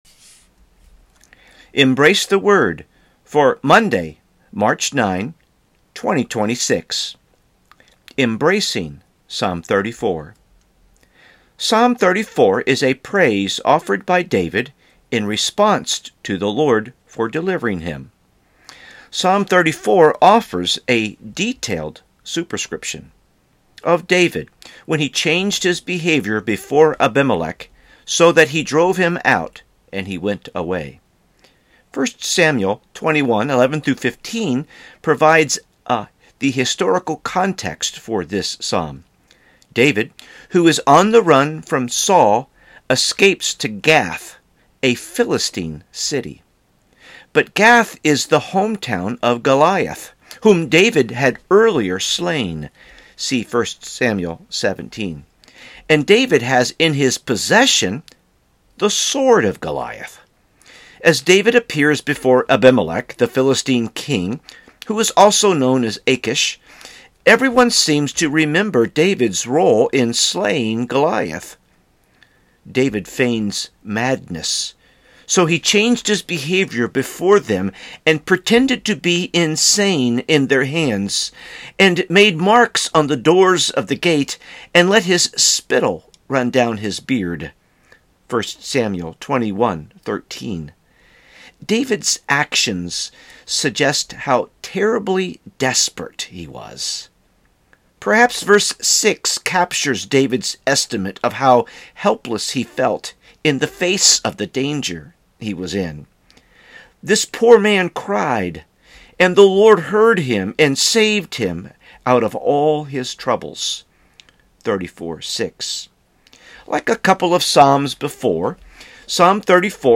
Sermons | First Baptist Church St Peters